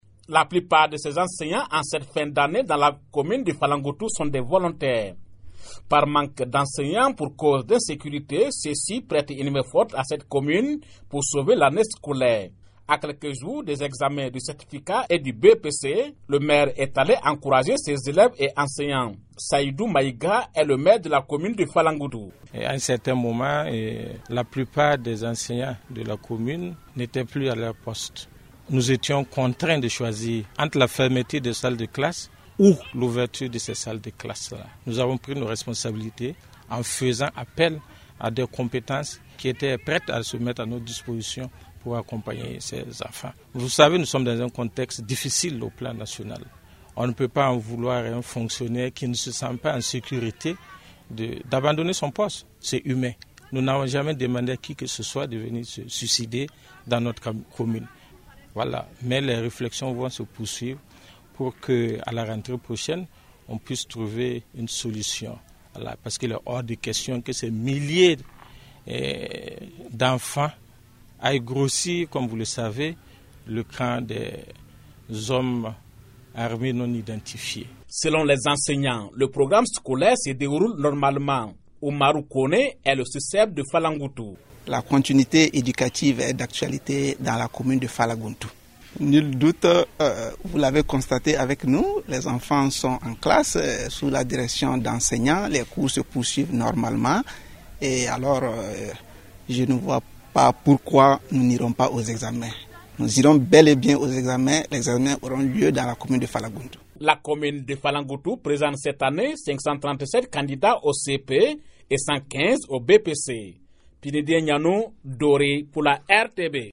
De Dori, le reportage